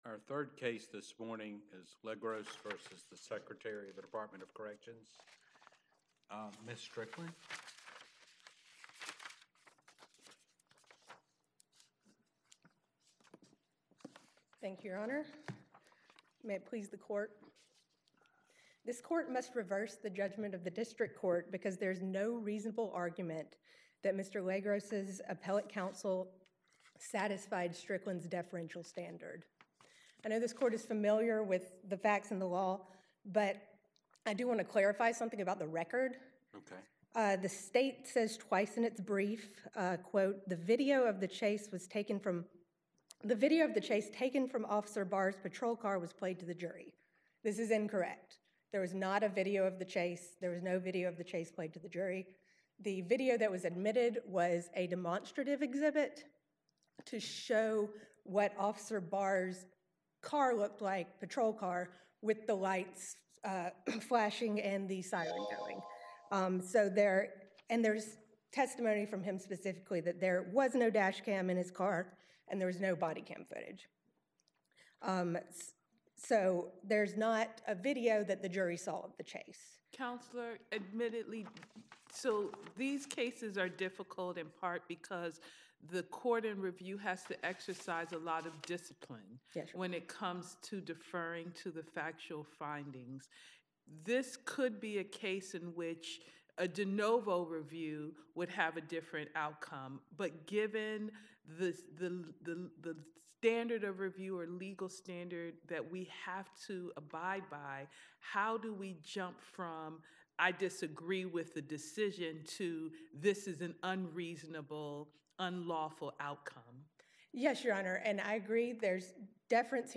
Oral Argument Recordings | United States Court of Appeals